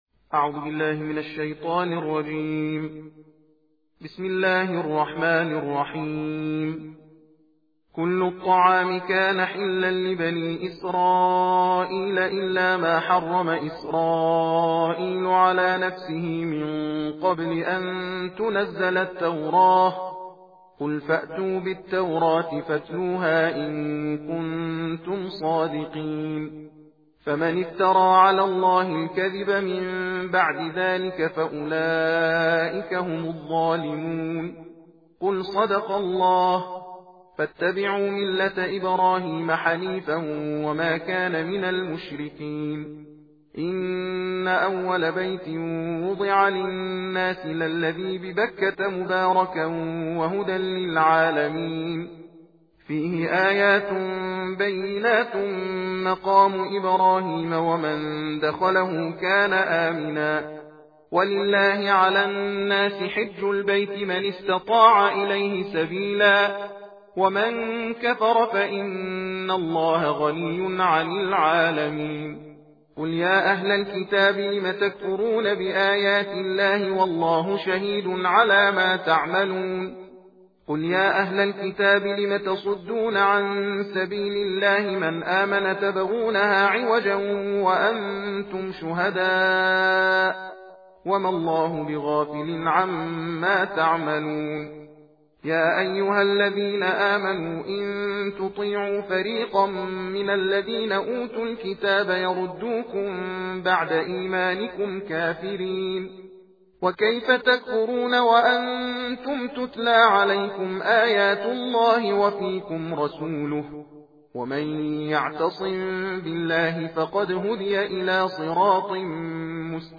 تندخوانی جزء چهارم قرآن کریم - مشرق نیوز